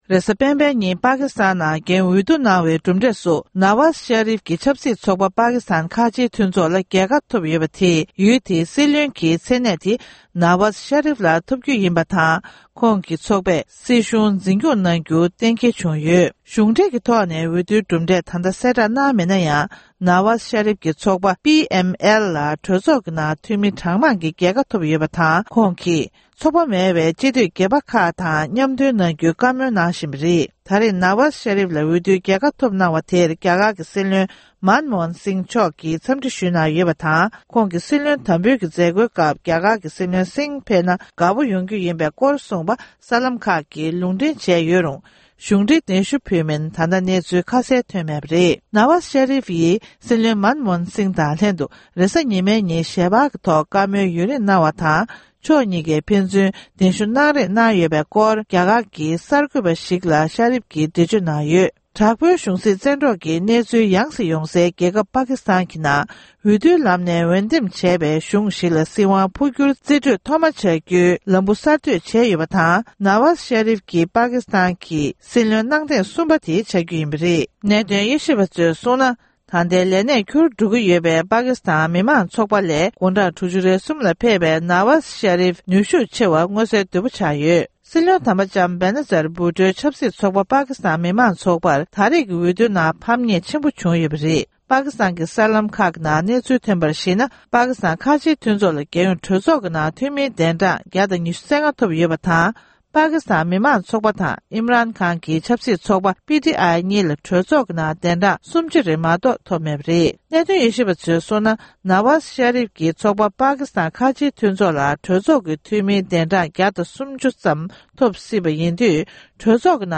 སྒྲ་ལྡན་གསར་འགྱུར།
གསར་འགྱུར་དཔྱད་གཏམ།